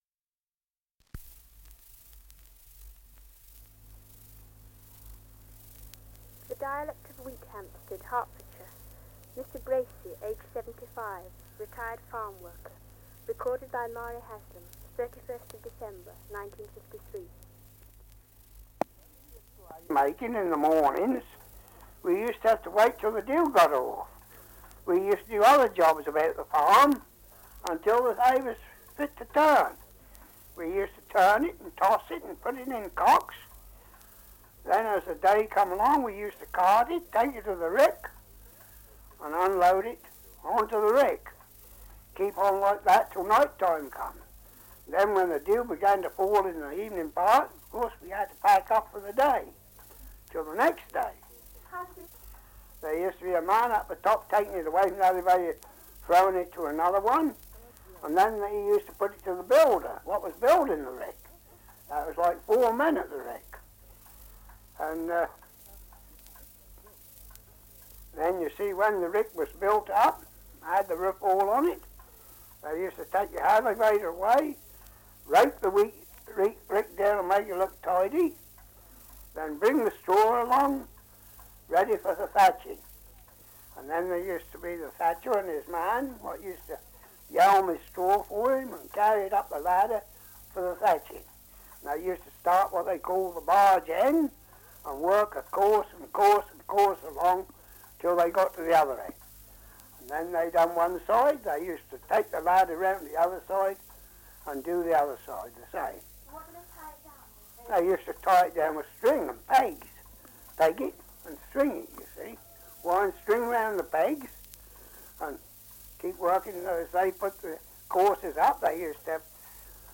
Survey of English Dialects recording in Wheathampstead, Hertfordshire
78 r.p.m., cellulose nitrate on aluminium